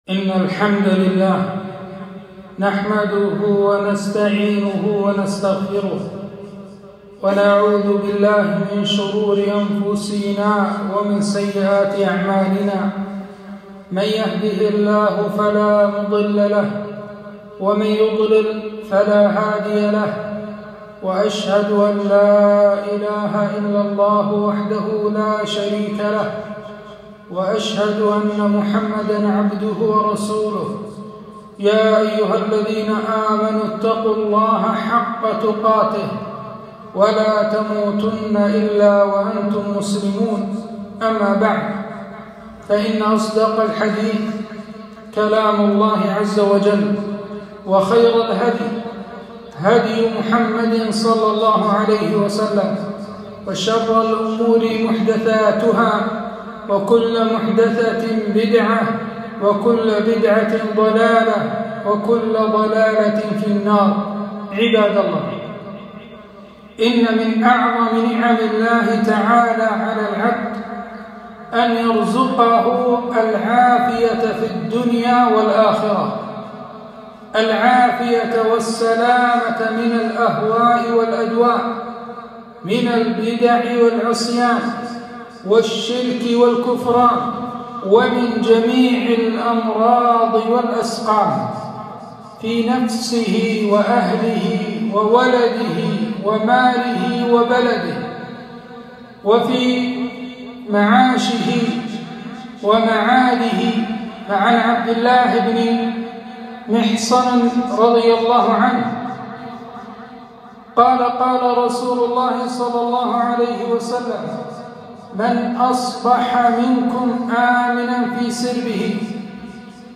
خطبة - سلوا الله العافية